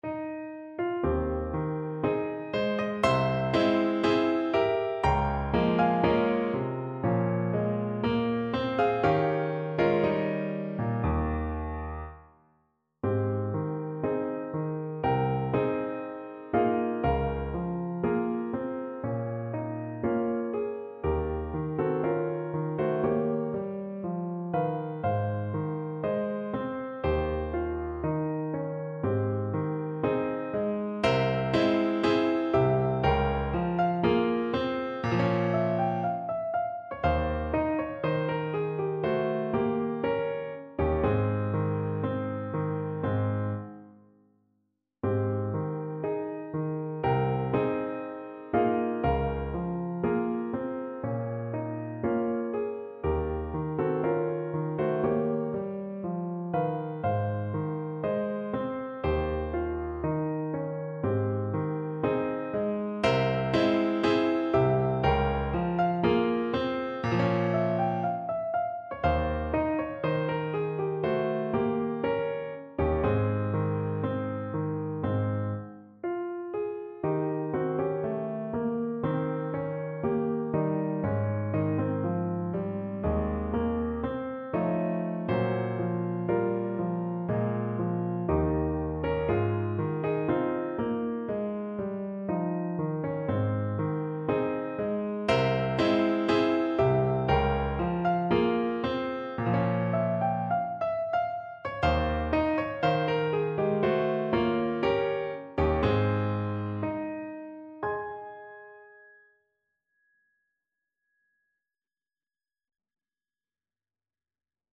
2/4 (View more 2/4 Music)
Andante =60
Classical (View more Classical Clarinet Music)